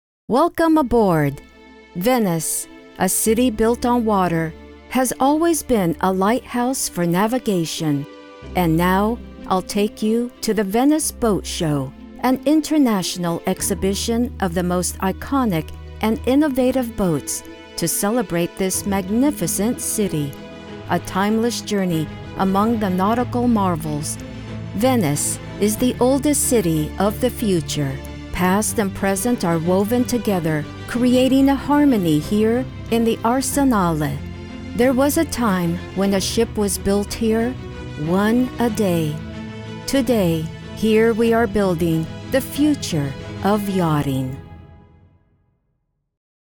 Documentary
Middle Aged
My voice is said to be warm, friendly, conversational, casual.